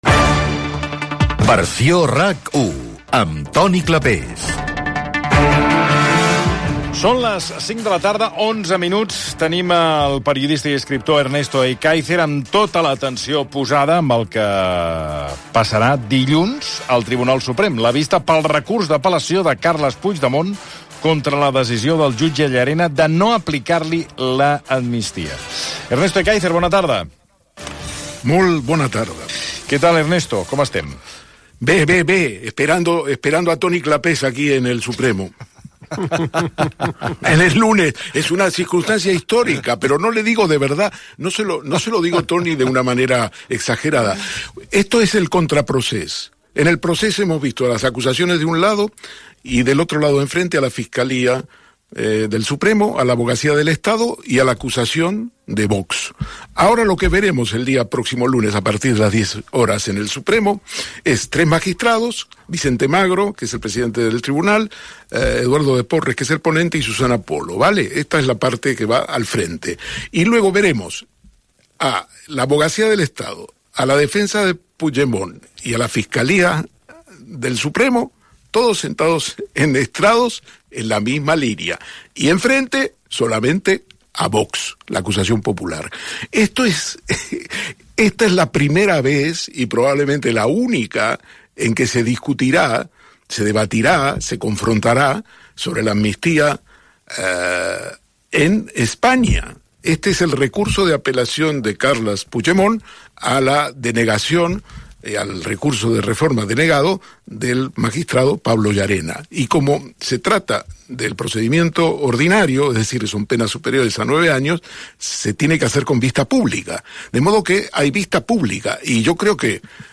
Indicatiu del programa, hora, el periodista Ernesto Ekaizer analitza què pot resoldre el Tribunal Suprem sobre el recurs de Carles Puigdemont per no haver estat amnistiat i quines poden ser els següents passos Gènere radiofònic Entreteniment